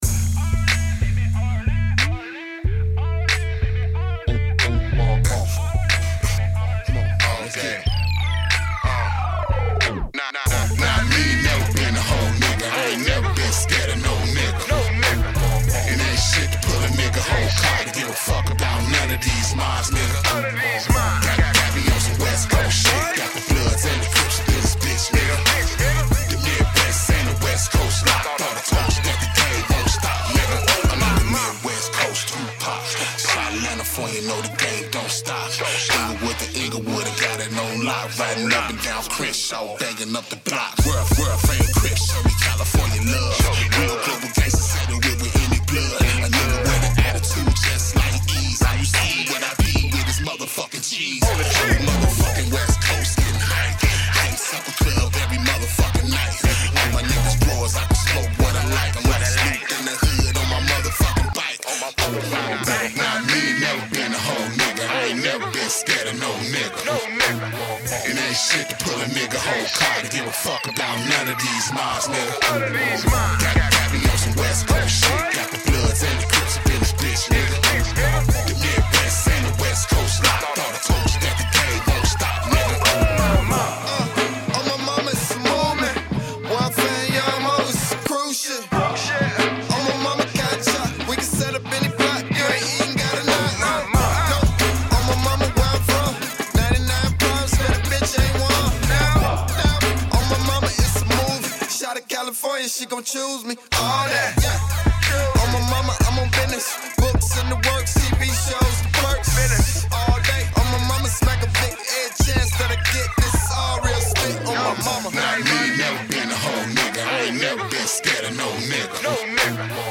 BPM: 183
epic banger